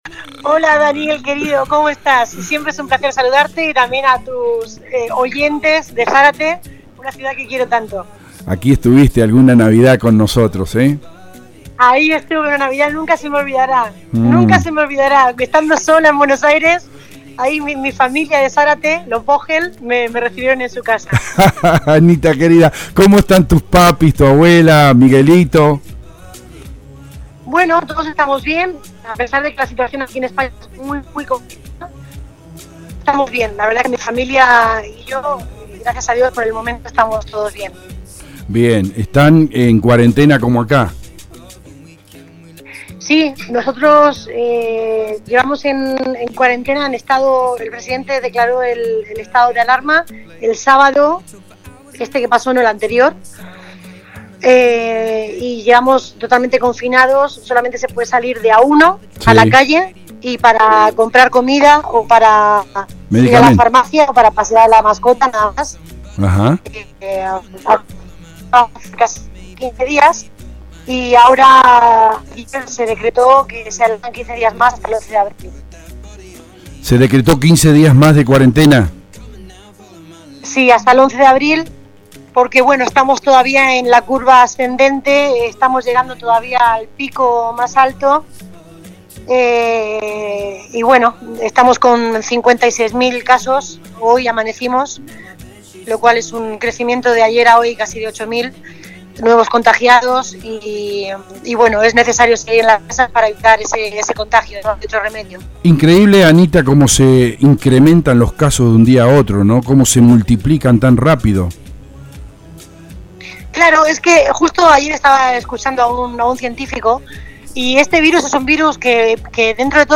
Así trajimos a nuestros micrófonos y al “aire de la radio”, desde el centro mismo de Madrid, para todos nuestros oyentes y lectores de Zárate y la región, a una madrileña de ley, artista que se ganó la Avenida Corrientes en la capital de Argentina y junto a Juan José Campanella su par, el argentino Luis Brandoni, descollaron con Parque Lezama en la capital española, la linda ciudad que hoy vive días tristes por el Coronavirus.